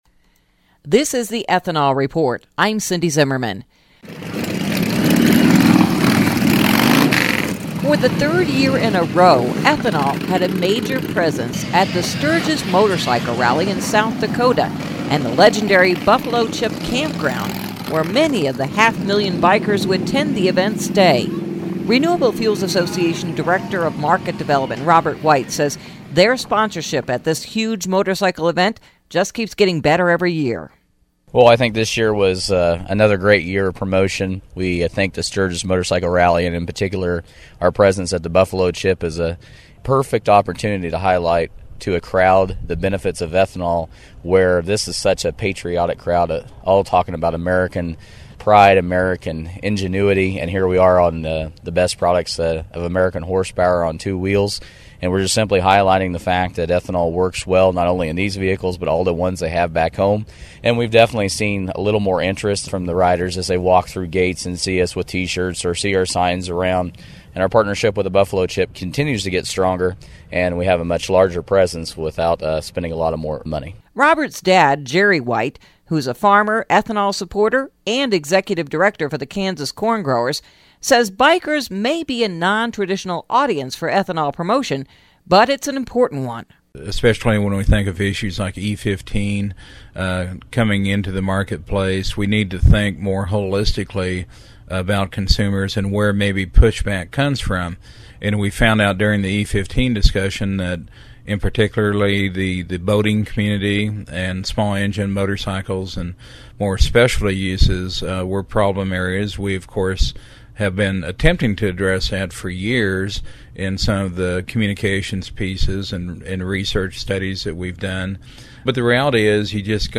This edition of “The Ethanol Report” features the 71st Annual Sturgis Motorcycle Rally held August 8-14 in Sturgis, SD.